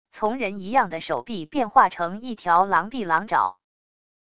从人一样的手臂变化成一条狼臂狼爪wav音频